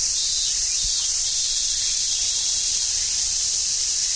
Add cicada sound (CC0)
sounds_cicada_04.ogg